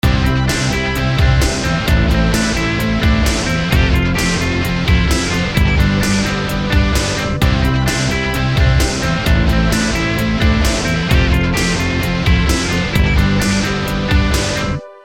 ↓それでこれにバッキングギターを3つ位重ねてみました。